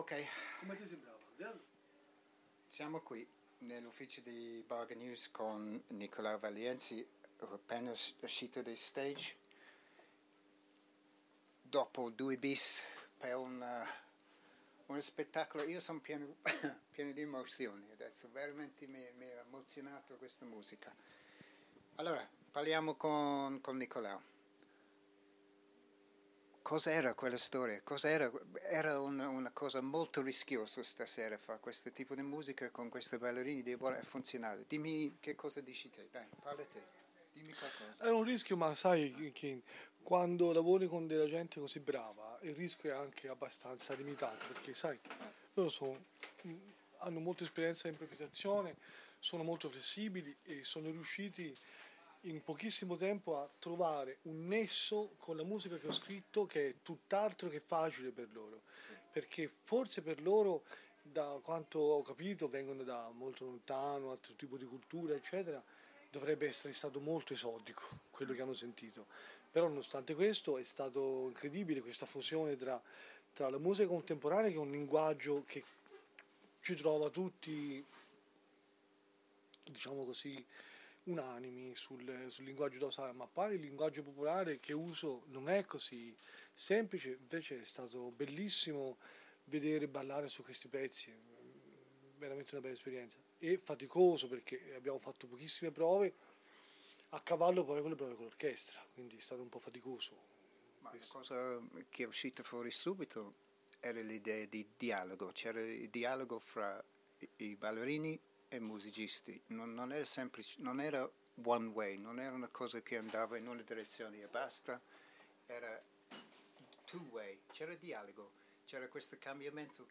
Conversation
just after coming off stage